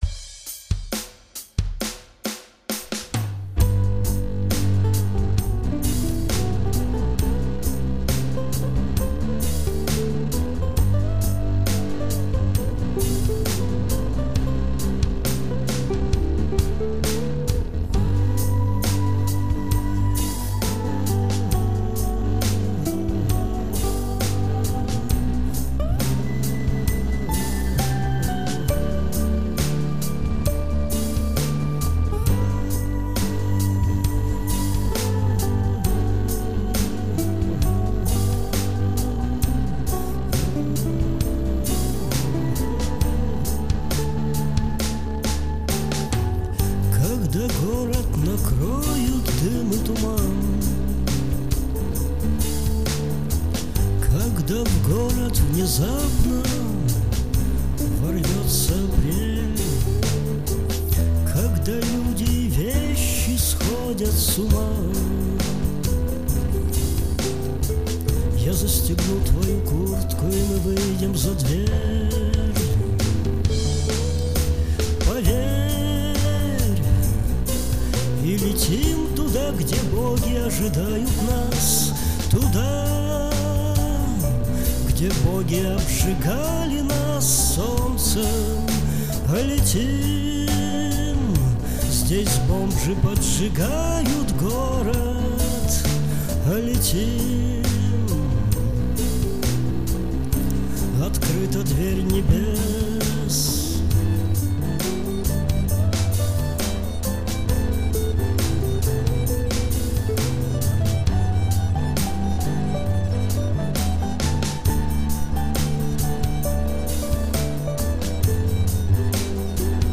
Арт-рок и авангард.